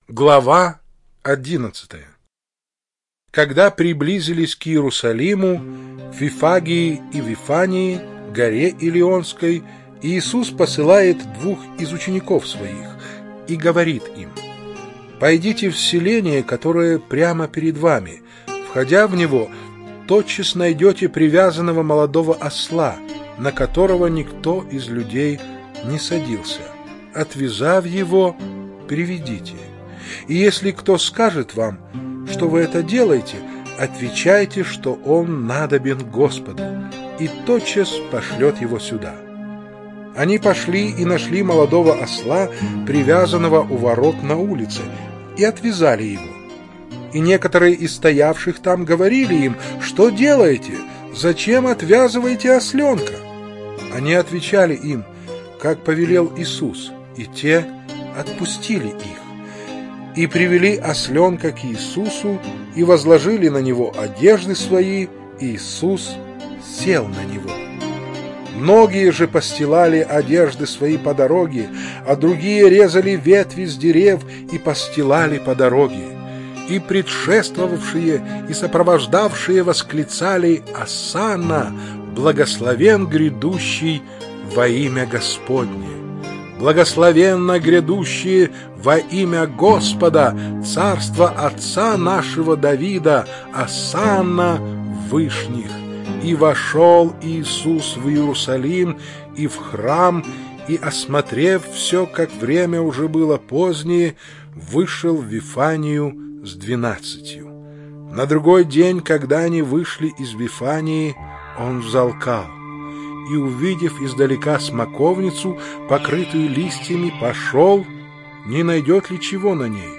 Чтение сопровождается оригинальной музыкой и стерео-эффектами